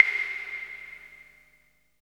50 CLAVE  -L.wav